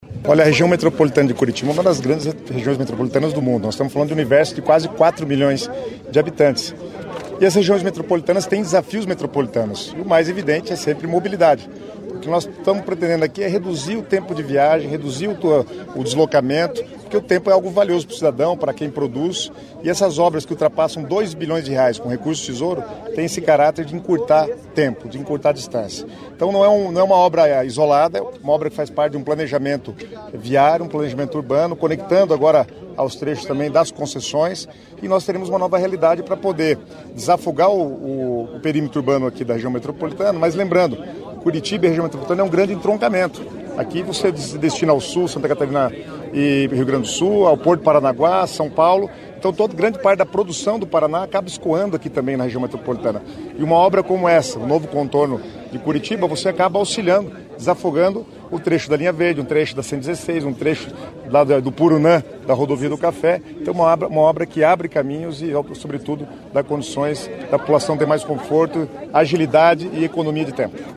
Sonora do secretário Estadual das Cidades, Guto Silva, sobre a autorização das obras do novo Contorno Sul de Curitiba